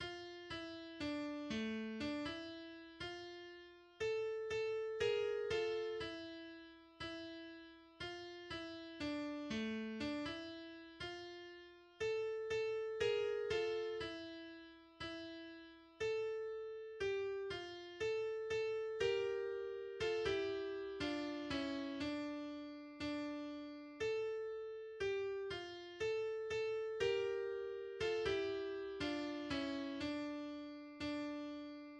Ez a dal a Színház- és Színművészeti Egyetem szabadsághimnusza. Magyar népdal, megváltoztatott szöveggel.